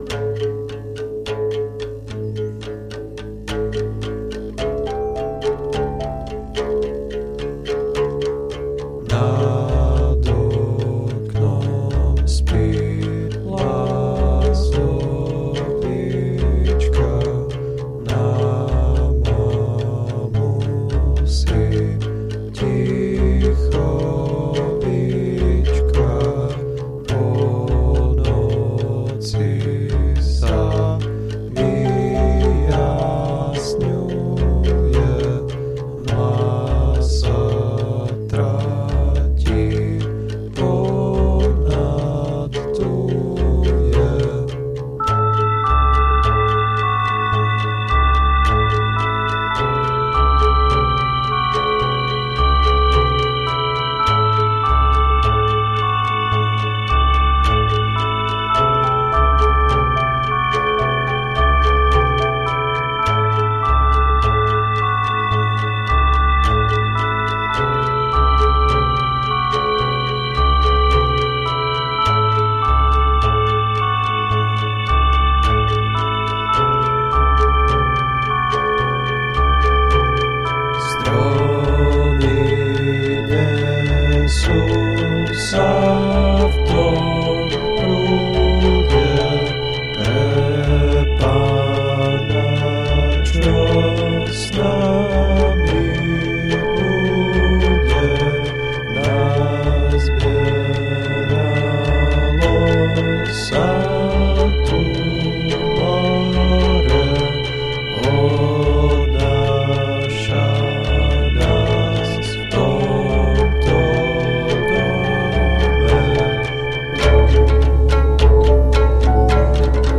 kategorie ostatní/písně